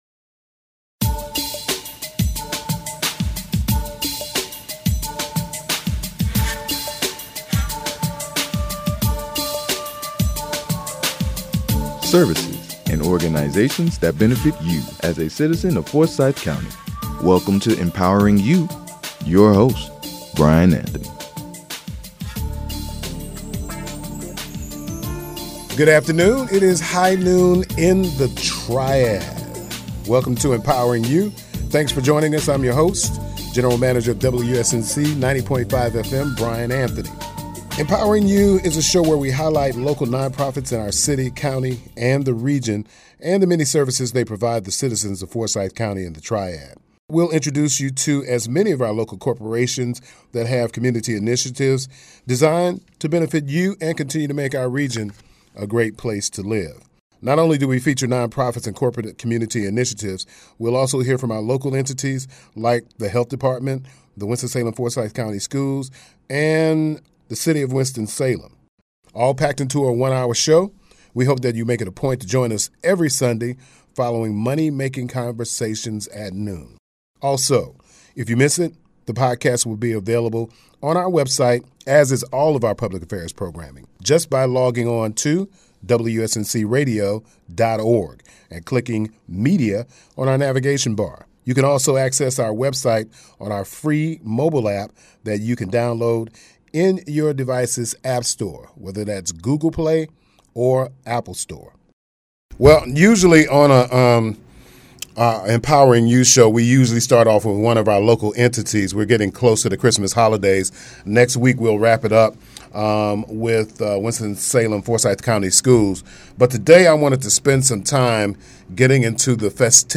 Empowering You is a 1-hour broadcast produced and recorded in the WSNC-FM Studios. The program is designed to highlight Non-Profit organizations, corporate community initiatives that are of benefit to the citizens of our community.